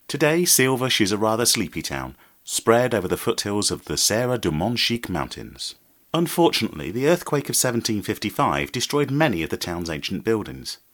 Travel Guide - Silves